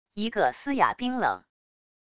一个嘶哑冰冷wav音频